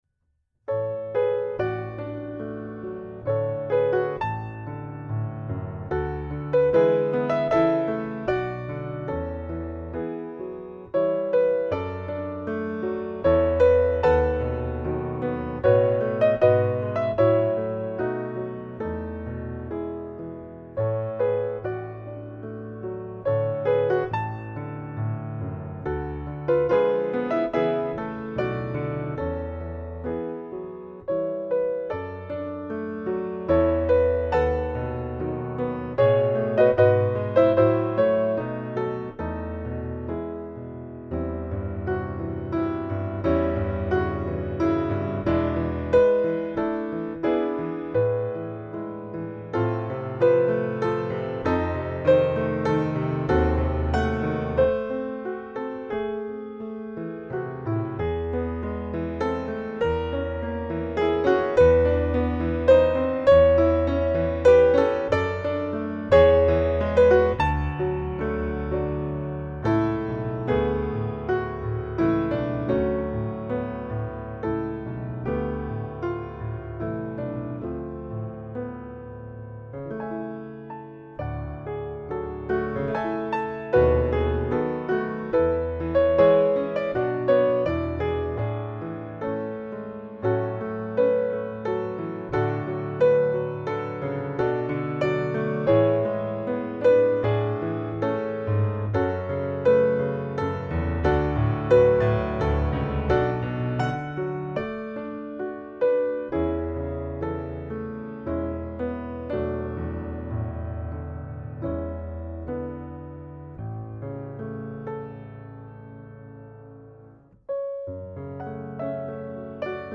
Piano Solo Song Without Words (Op. 102, #4) by Felix Mendelssohn Humoreske (Op. 61, #2) by Edvard Grieg Intermezzo (Op. 118, #2) by Johannes Brahms Piano and Vocal E Lucevan Le Stelle Il Lamento di Federico You Raise Me Up